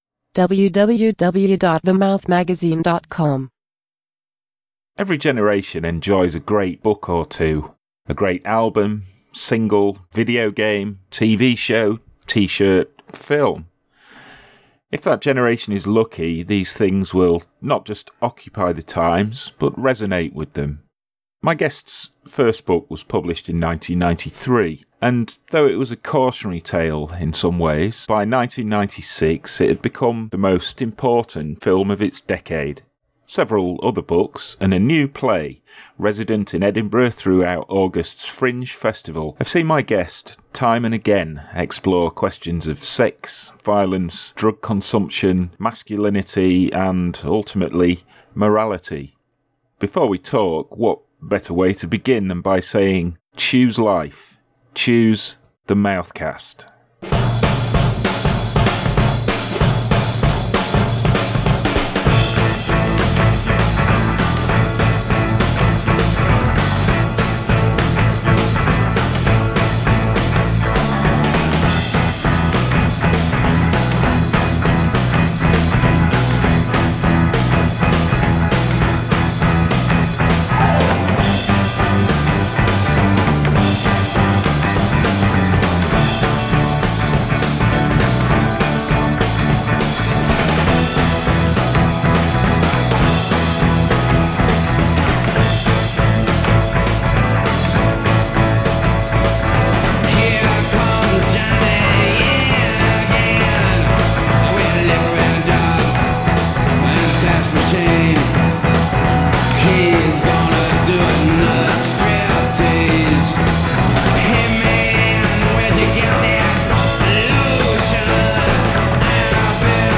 PERFORMERS is a dark and subtle comedy narrating the story of two unwitting gangsters who find themselves auditioning for a part in the film. In this new edition of The Mouthcast, we begin with Welsh discussing both PERFORMERS and PERFORMANCE, before opening up a wider discussion on some of the recurring themes of his work…